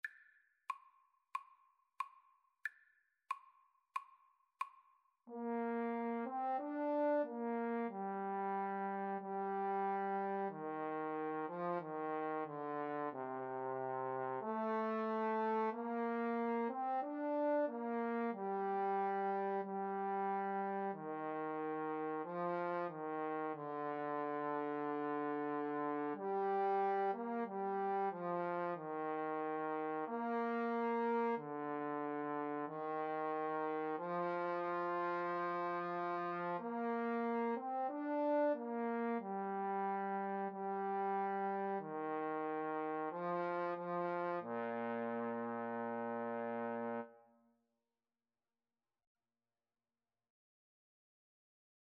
Andante = c. 92
Classical (View more Classical Trumpet-Trombone Duet Music)